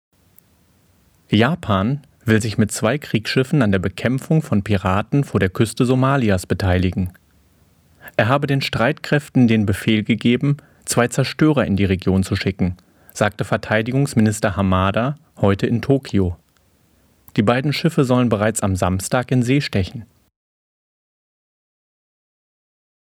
Profi-Sprecher und Schauspieler mit wandelbarer Stimme von seriös bis böse
Sprechprobe: Industrie (Muttersprache):